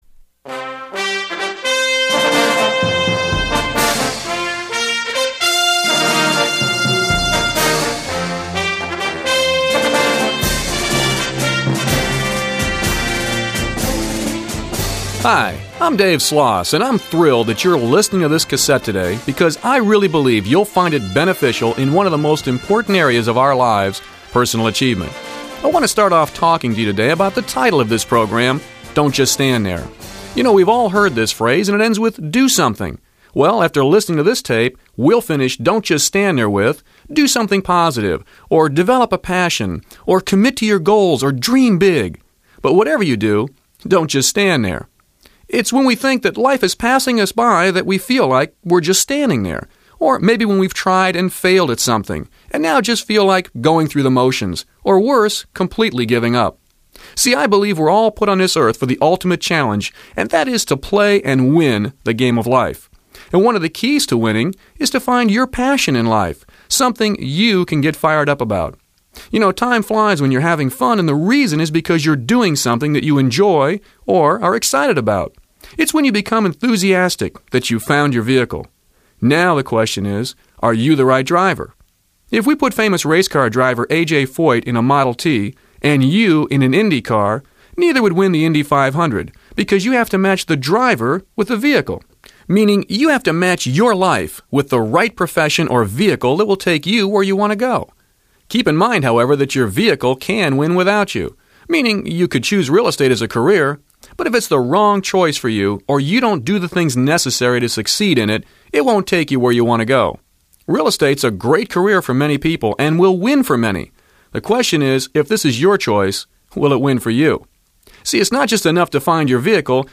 Motivational Audio Books